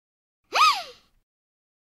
Звуки удивления, мультфильмов
Мультяшное ииих для видеомонтажа